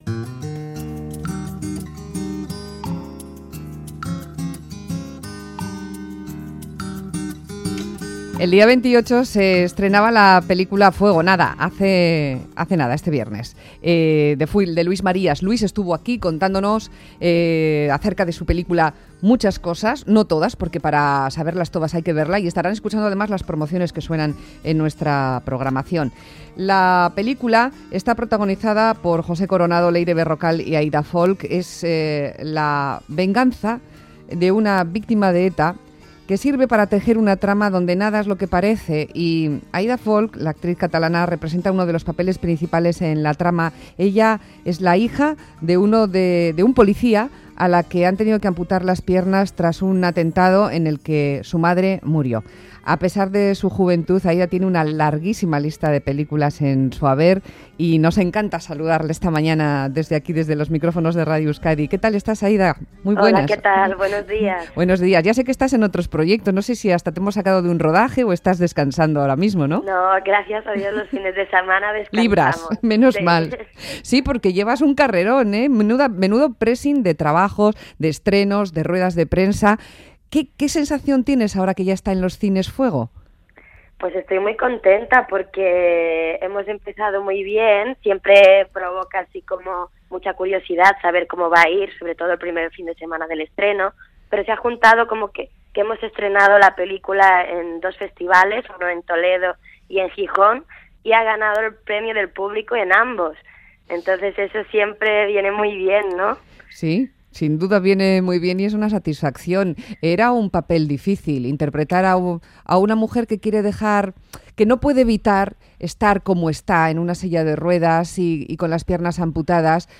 Entrevista Aida Folch película Fuego | Más que Palabras Radio Euskadi
Conversamos con la actriz catalana Aida Folch, a quien podemos ver junto a José Coronado, interpretando a una víctima de ETA, en la película "Fuego".